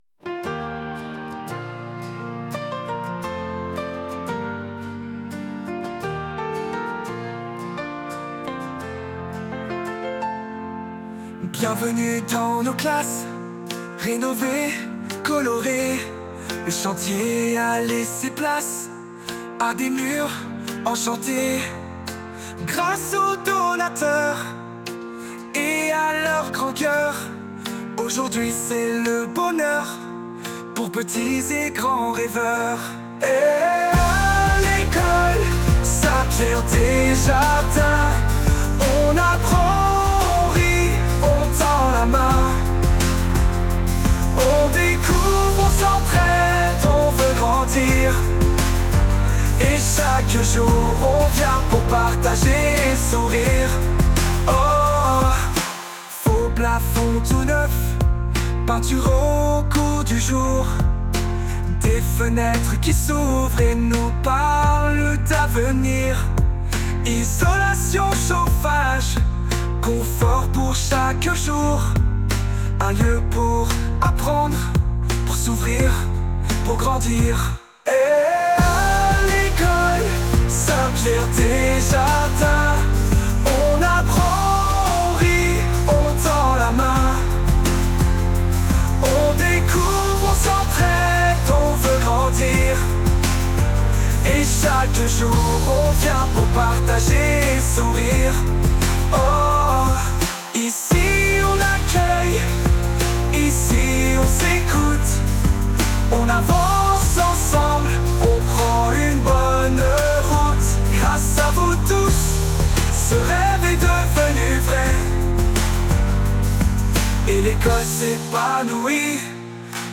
Vendredi 19 septembre, nos classes rénovées ont été officiellement inaugurées !
Photos (13) Audio (1) Votre navigateur ne peut pas lire ce son : Download it 2. chant inauguration.mp3 Ecrire un commentaire Aucun commentaire